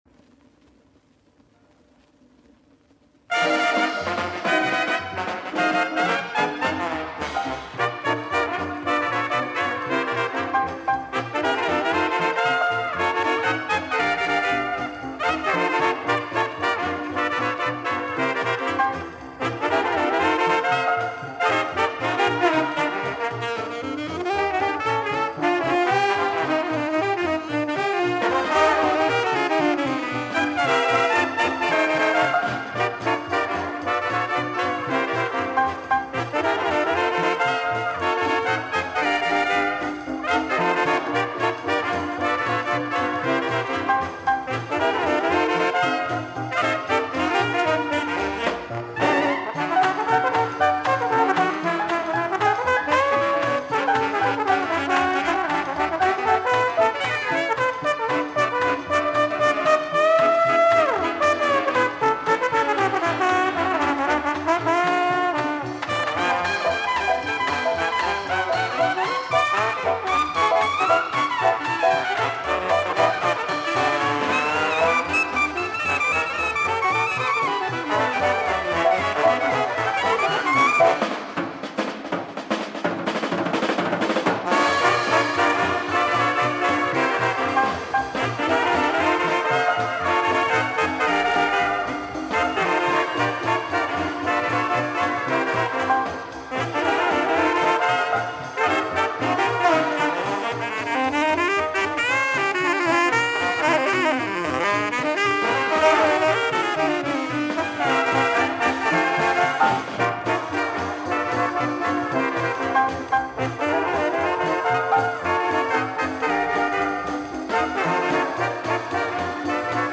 инстр. ансамбль